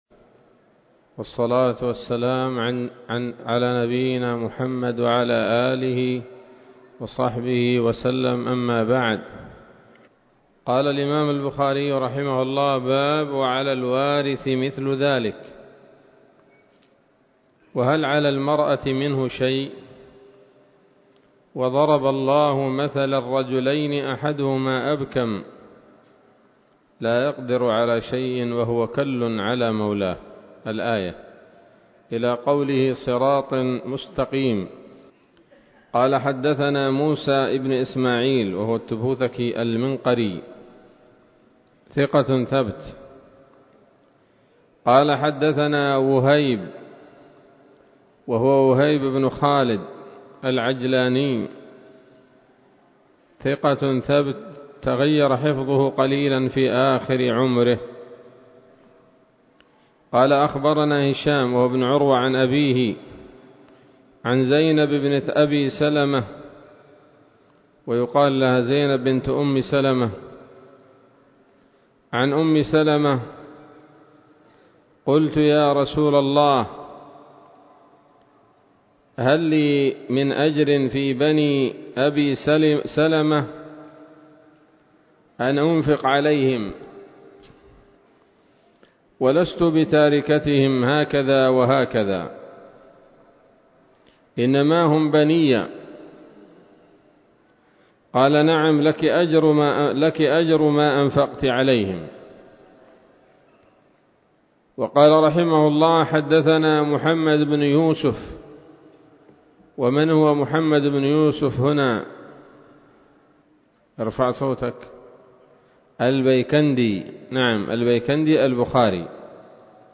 الدرس الحادي عشر من كتاب النفقات من صحيح الإمام البخاري